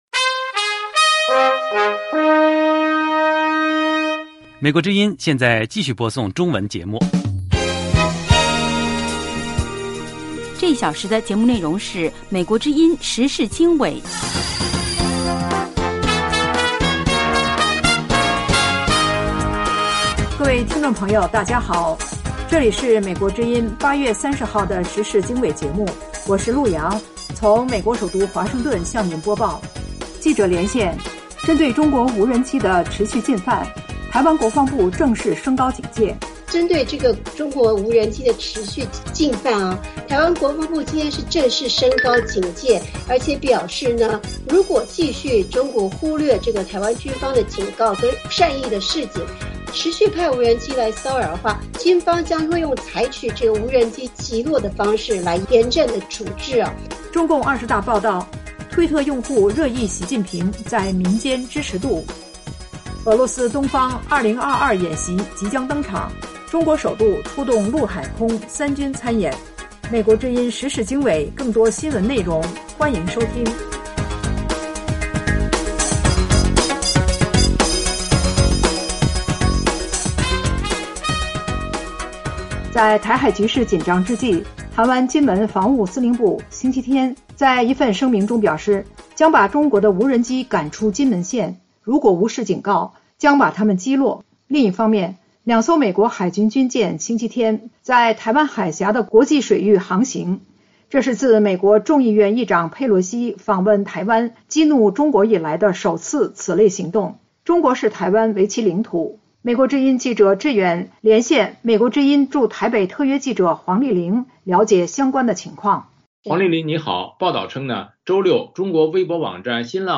时事经纬(2022年8月30日)：1/记者连线：针对中国无人机的持续进犯 台湾国防部正式升高警戒。2/中共二十大报道：推特用户热议习近平在民间支持度。